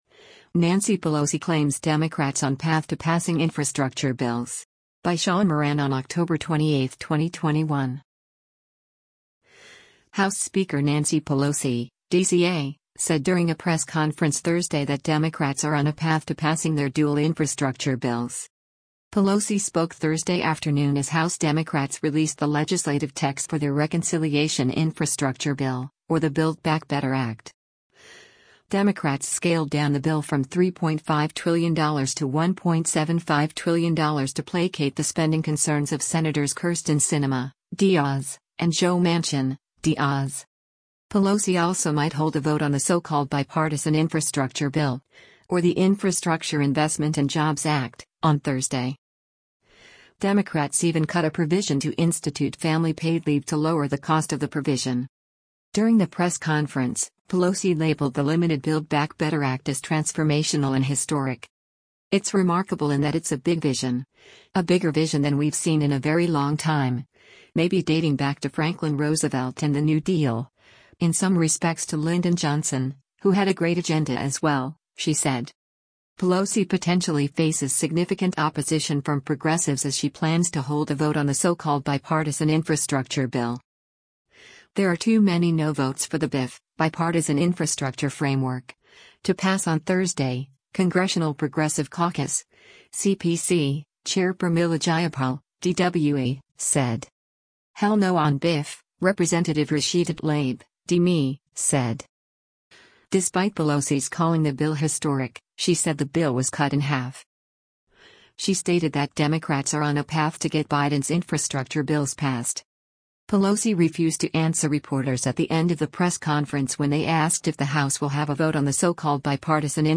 House Speaker Nancy Pelosi (D-CA) said during a press conference Thursday that Democrats are on a “path” to passing their dual infrastructure bills.
Pelosi refused to answer reporters at the end of the press conference when they asked if the House will have a vote on the so-called bipartisan infrastructure bill on Thursday.